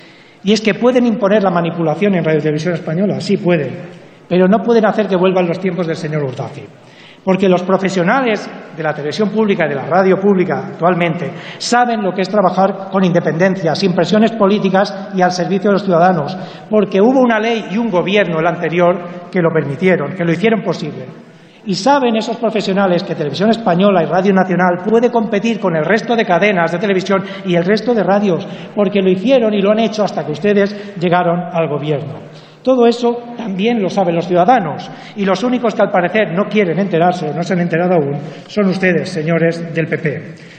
Fragmento de la intervención de Germán Rodríguez en el pleno del 17/2/2015 en defensa de una proposición de ley par recuperar la independencia de la Corporación RTVE y el pluralismo en la elección parlamentaria de sus órganos